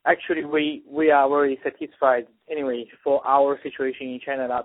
THIS MAN IN CHINA SAYS MOST ON THE MAINLAND ARE NOT BE INCLINED TO PROTEST BECAUSE THEY ARE ECONOMICALLY SATISFIED.